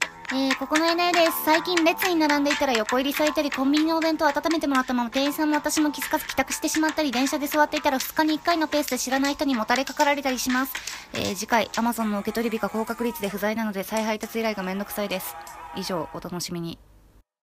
アドリブ声劇！17秒でTVアニメの次回予告！